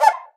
BCUICA H.wav